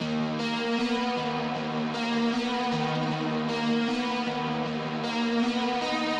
Party Monster Melody (155 BPM C# Major).wav